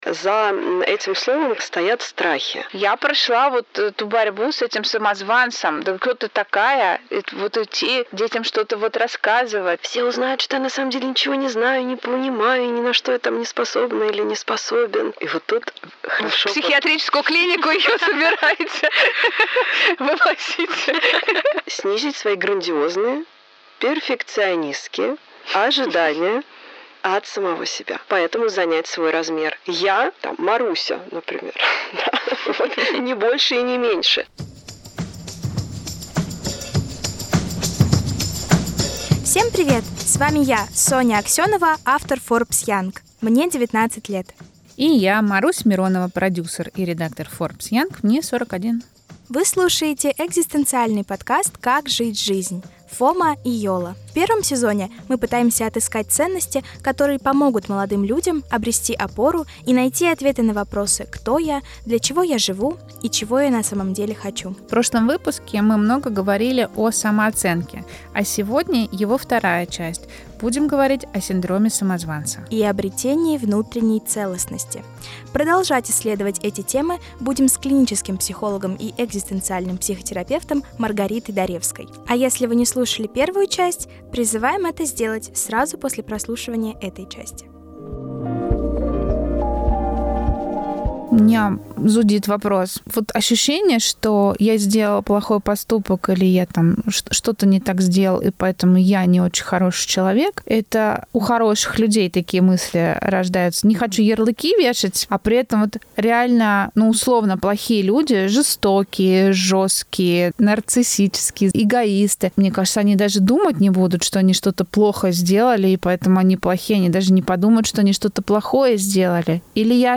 Разбираем истории и личный опыт ведущих и подписчиков Forbes Young вместе с клиническим психологом и экзистенциальным психотерапевтом